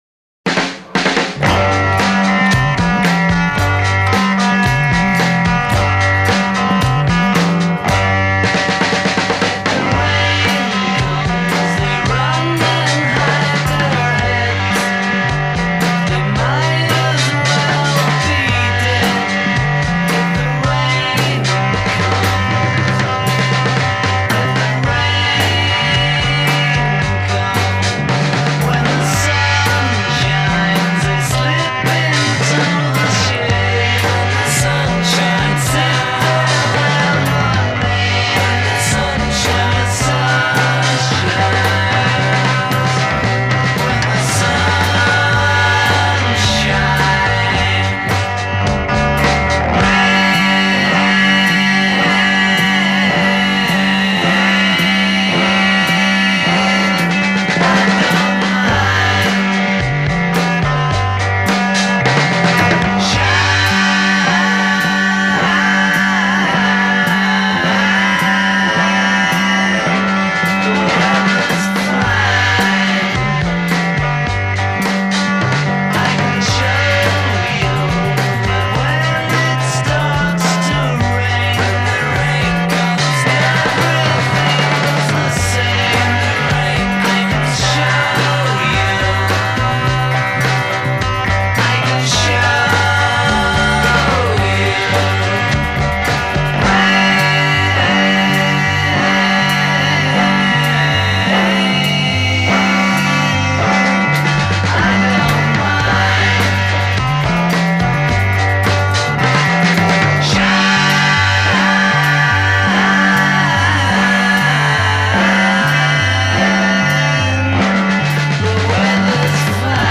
Studio Three, 14 April 1966: vocals, drums, bass, and guitar
Studio Two, 16 April 1966: vocals, tape overdub, and bass
intro     Drums, guitar, tambourine, and bass.
A verse     Double-tracked solo voice over ensemble. a
A verse     As above with responding chorus. b
Two-part harmony.
Triplet bass. c
Tambourine doubles. d